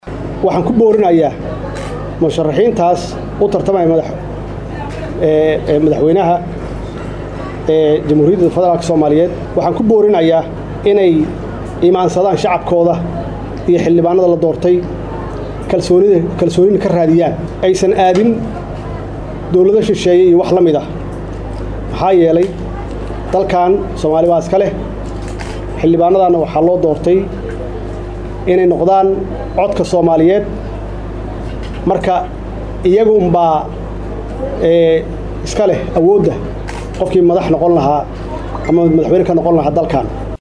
December, 20 2016 (Puntlandes)-Musharaxa xilka madaxtinimada ee Soomaaliya Maxamed Cabdullaahi Farmaajo oo lahadlayey boqolaal shacab ah xilli lagu soodhoweeyey magaalada Muqdisho ayaa fariin kulul u diray musharixiinta laloolamaysa.